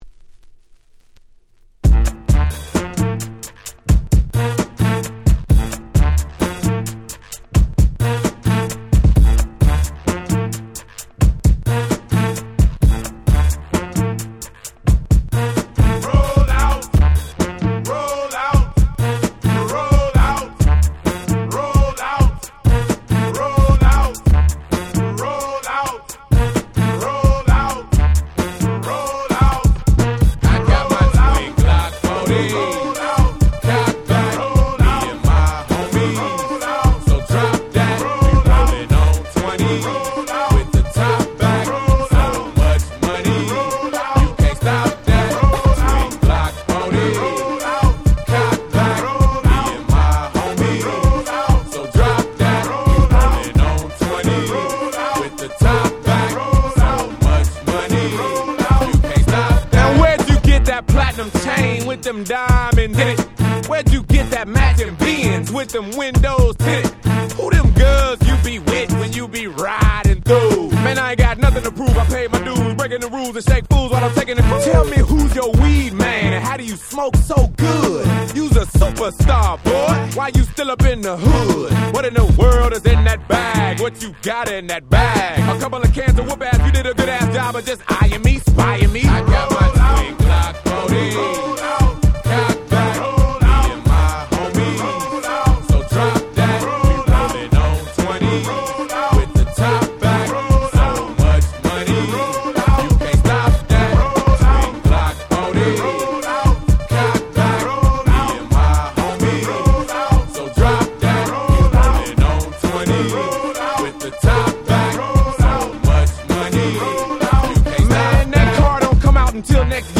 01' Big Hit Hip Hop !!